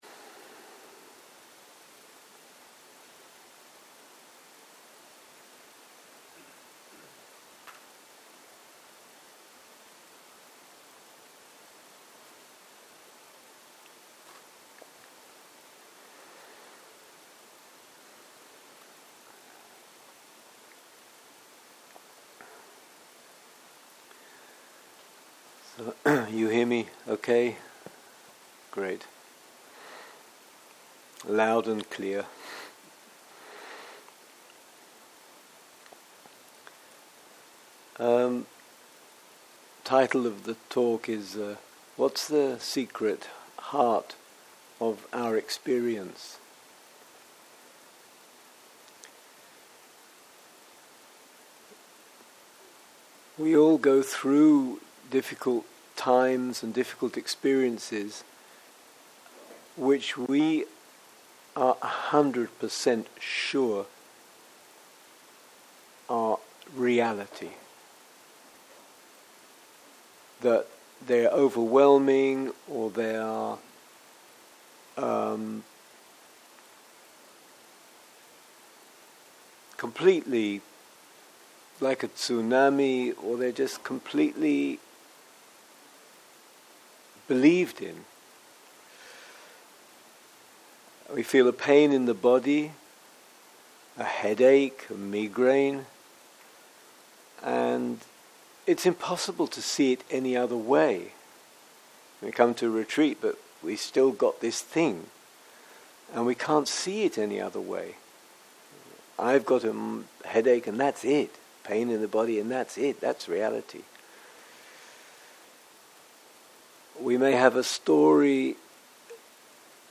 type: Dharma Talks